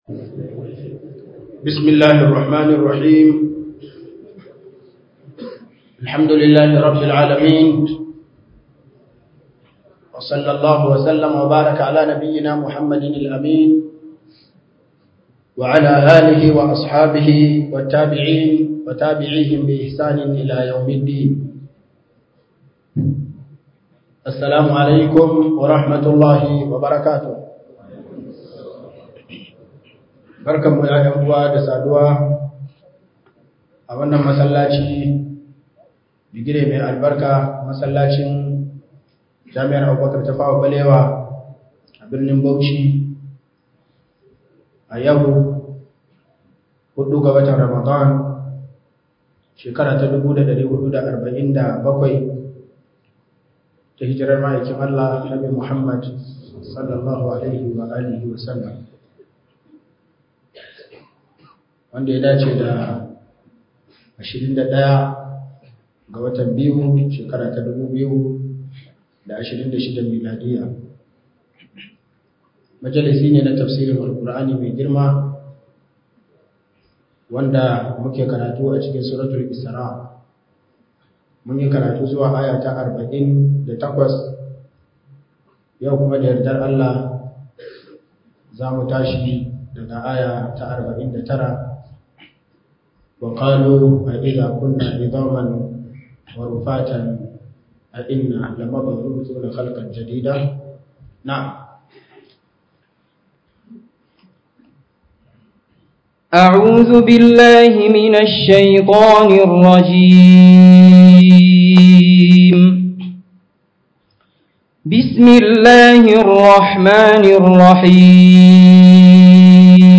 004 Ramadan Tafsir - Surah Isra'i